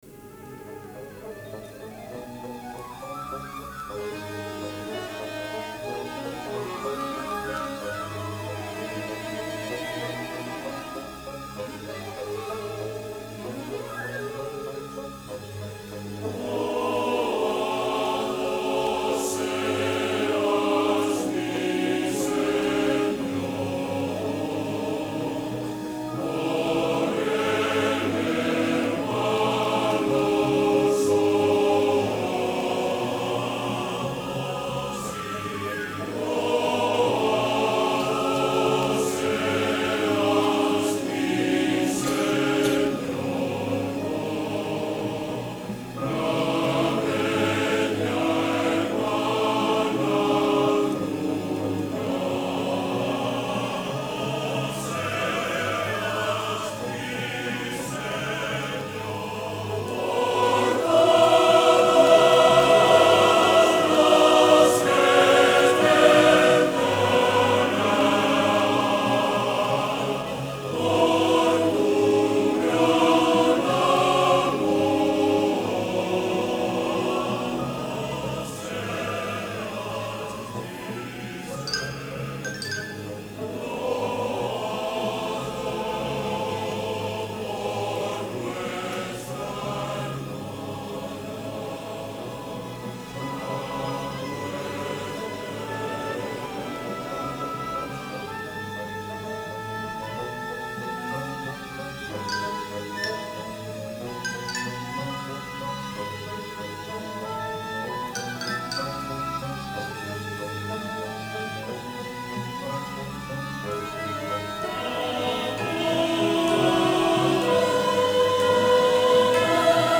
Liedari eskainitako jardunaldi bat ere izan zen, eta, amaieran, Donostiako La vie profonde de Saint François d’Assise obra handiaren berreskuratzea nabarmendu zen.
Int. tenor
ahotsa
Kaputxinoen abesbatza
Euskadiko Orkestra Sinfonikoa.